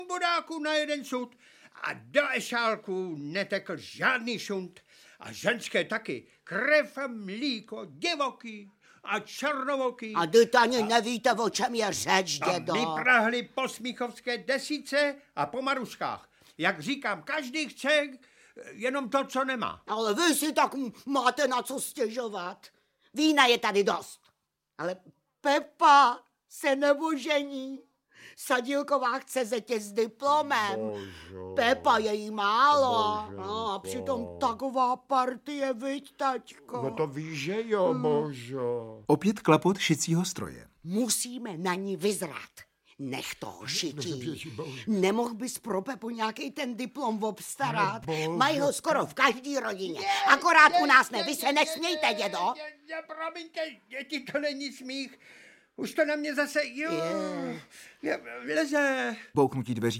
Audiobook
Read: Lubomír Lipský